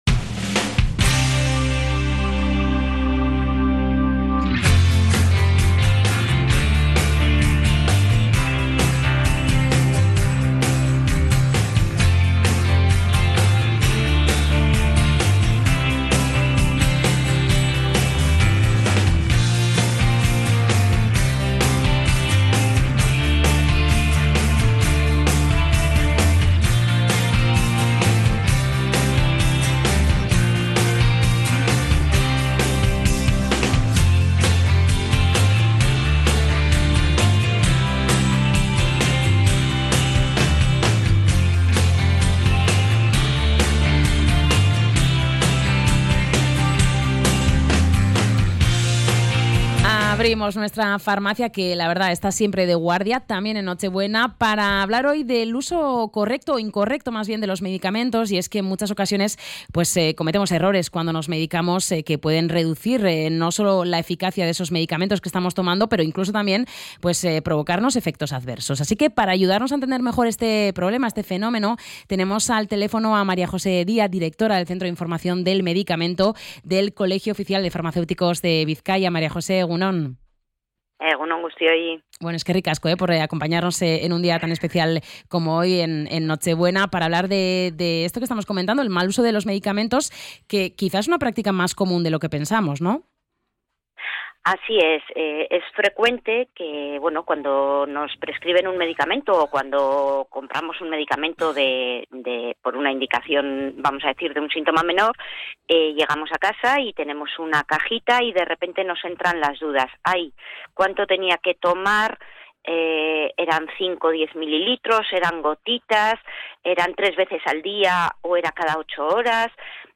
Ha explicado que un jarabe es un medicamento y que no vale tomarlo “a modo de chupito”.